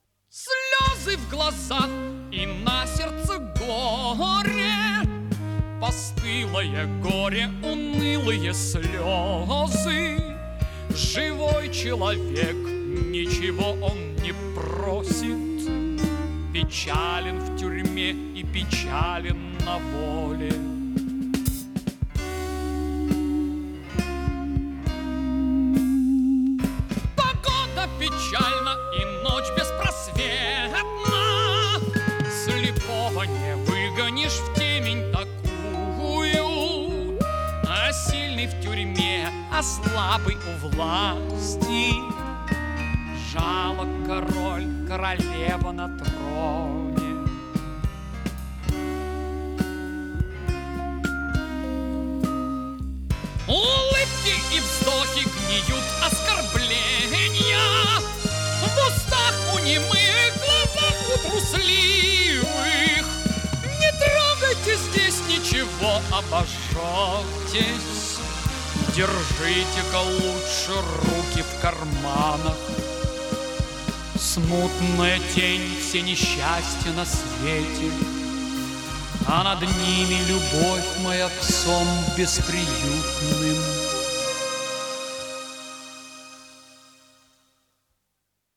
Жанр: Rock, Pop
Стиль: Art Rock, Ballad, Vocal, Classic Rock
Вокальная сюита на стихи Поля Элюара